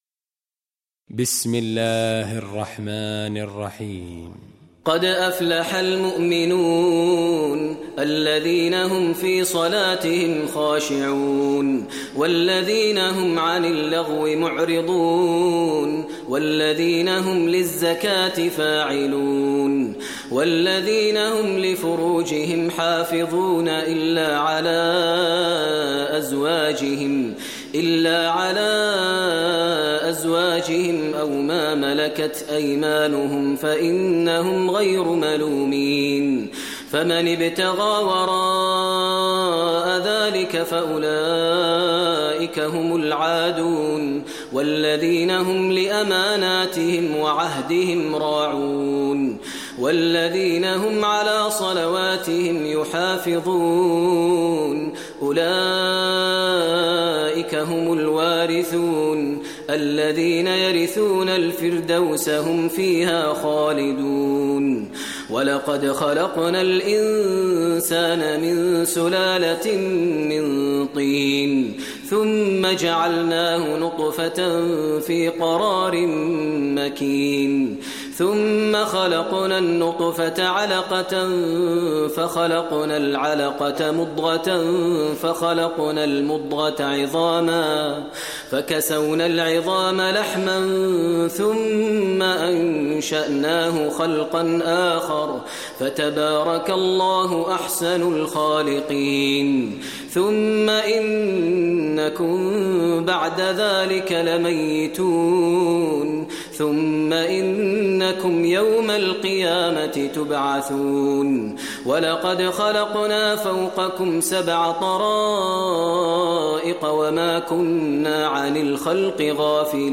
Surah Muminun Recitation by Maher Mueaqly
Surah Muminun, listen online tilawat / recitation in Arabic, recited by Imam e Kaaba Sheikh Maher al Mueaqly.